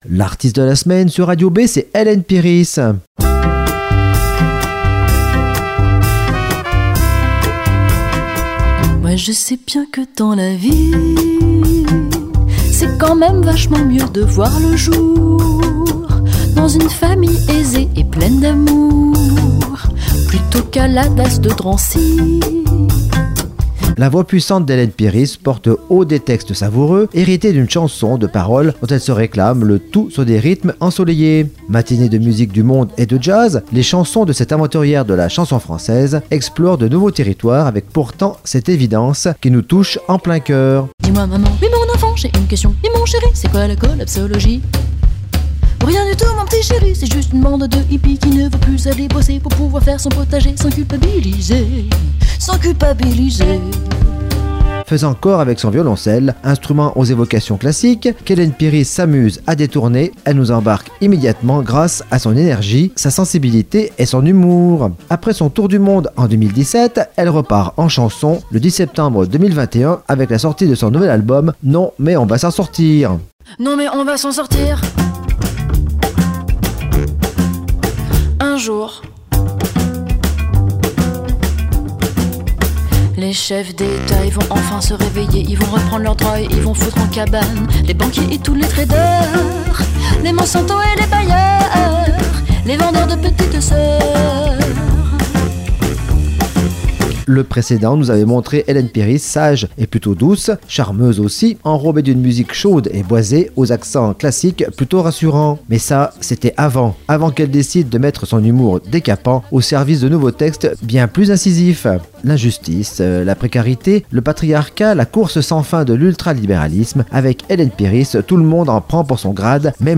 Mâtinées de musique du monde et de jazz, les chansons de cette aventurière de la chanson française explorent de nouveaux territoires avec pourtant cette évidence qui nous touche en plein cœur.
Et signe un album drôle et musical.